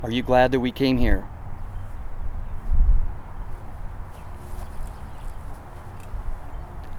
About this clip: Recorded at Linwood Cemetery, Cedar Rapids.
First a very faint whisper is heard in reply, followed by a startling, aggressive-sounding and very profane response from someone else.
This is the raw, untouched file - exactly as recorded that day. Angry entity at young couple's grave (profanity) About 2.5 seconds into the clip a faint whisper is heard, that sounds like "You're fine."